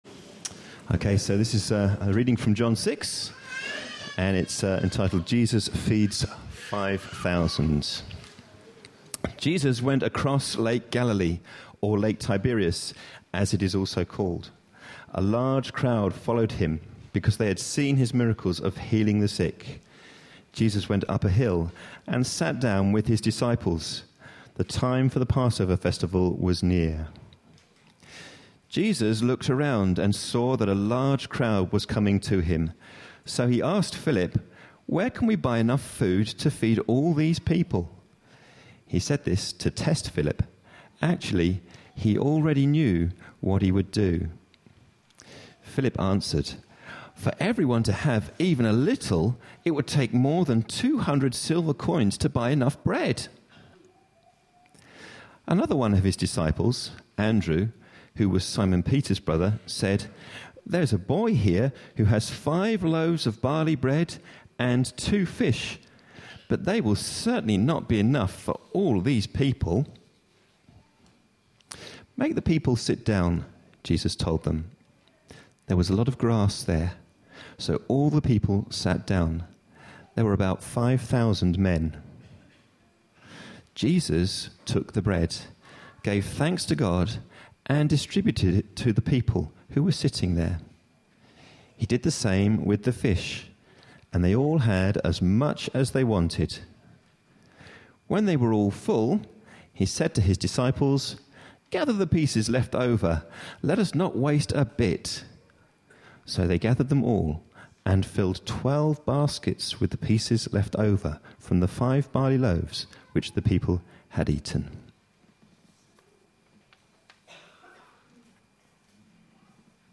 A sermon preached on 20th October, 2013.
John 6:1-13 Listen online Details Reading is John 6:1-13, followed by a dramatic monologue, and a brief talk with a recorded insert. (This was an All-Age service.)